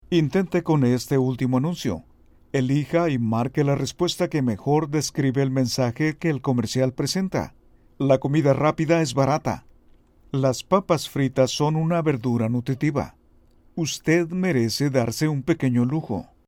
Intente con este último anuncio. Elija y marque la respuesta que mejor describe el mensaje que el comercial presenta.